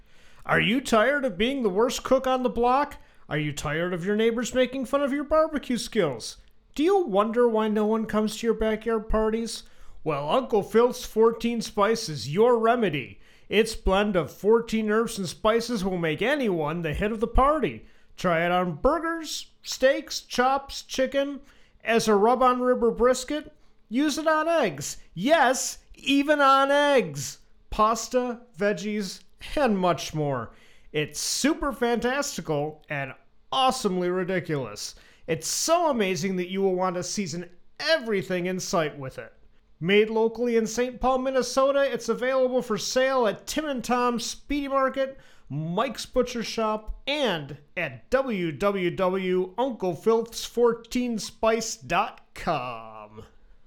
Radio Ad!